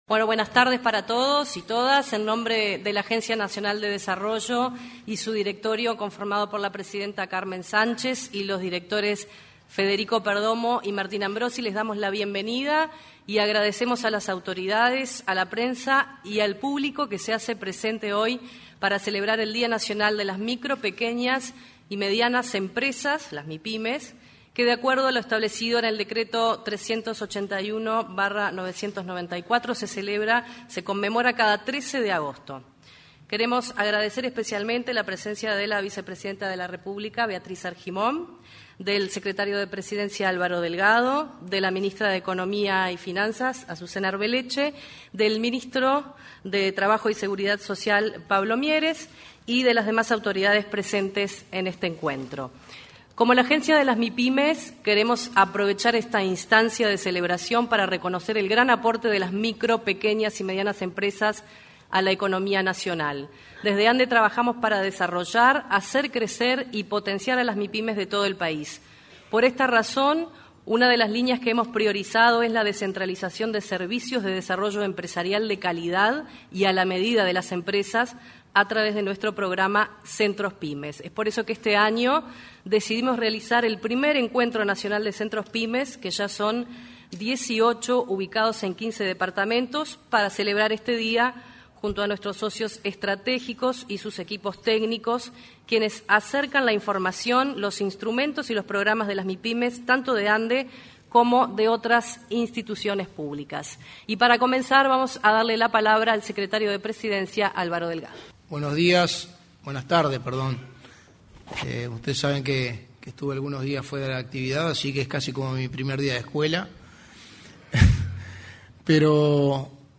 Primer Encuentro Nacional de Centros Pymes 15/08/2022 Compartir Facebook X Copiar enlace WhatsApp LinkedIn En el salón de actos anexo de la Torre Ejecutiva tuvo lugar la celebración del Día Nacional de la Micro, Pequeñas y Medianas Empresas.